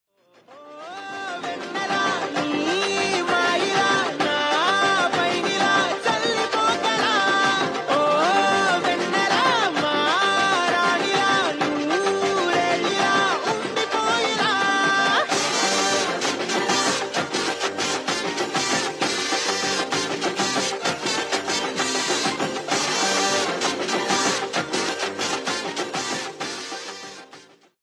best flute ringtone download
romantic ringtone download
melody ringtone